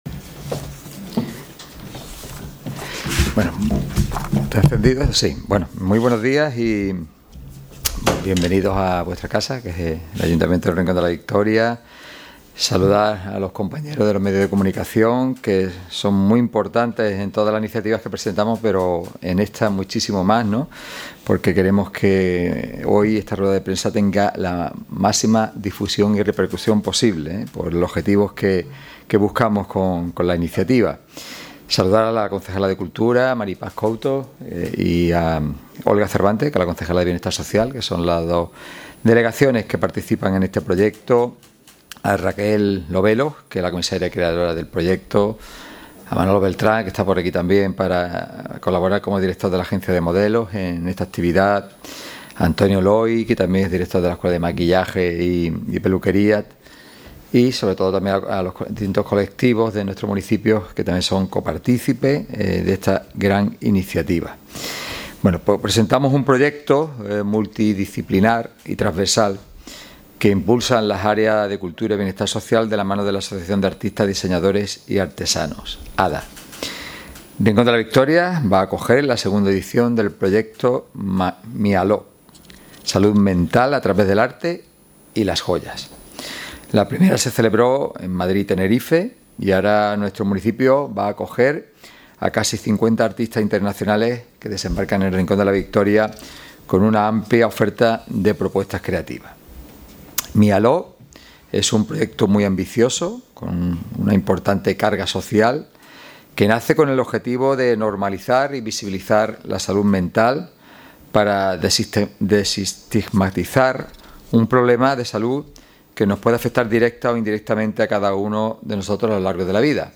Rueda-de-prensa-Maylo.mp3